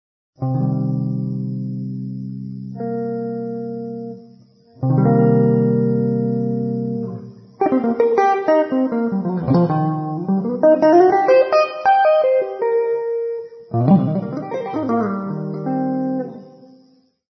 Se invece alla triade minore (C,Eb,G) addizioniamo una terza minore avremo C,Eb,G,Bb: trattasi di un C-7, un accordo minore settima [